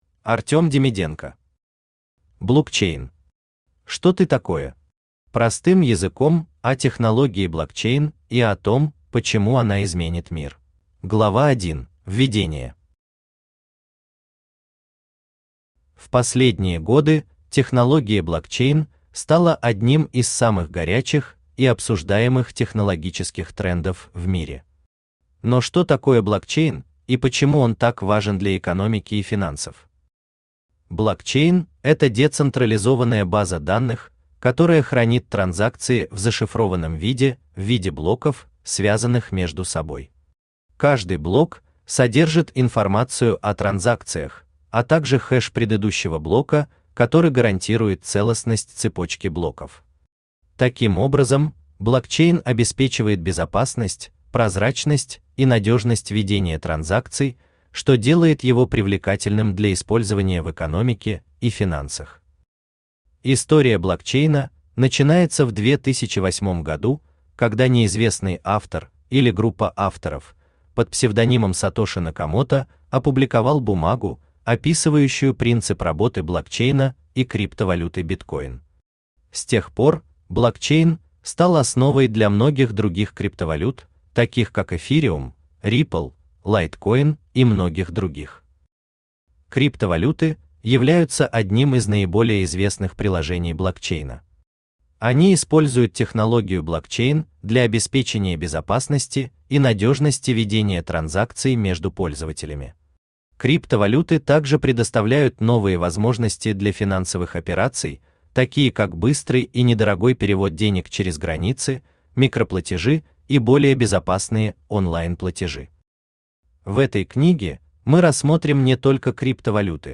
Простым языком о технологии блокчейн и о том, почему она изменит мир Автор Искусственный Интеллект Читает аудиокнигу Авточтец ЛитРес.